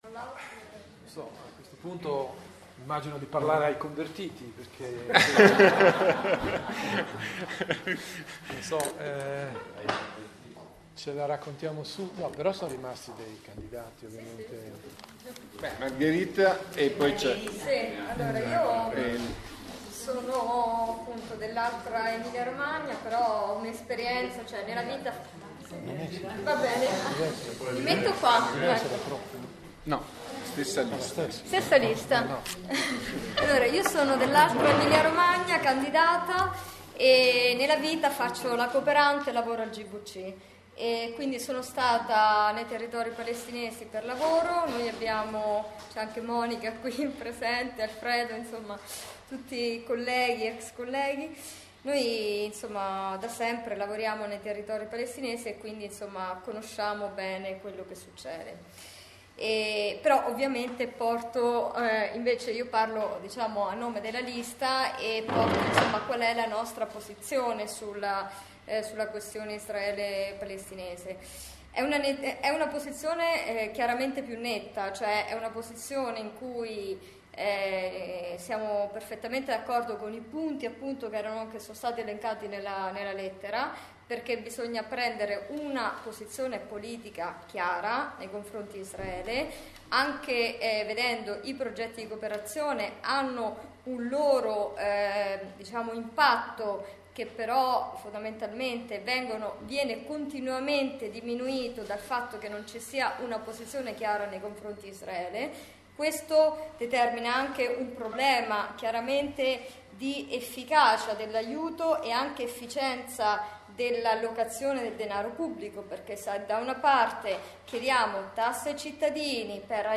CONFRONTO CON LE/I CANDIDATE/I ALLE ELEZIONI REGIONALI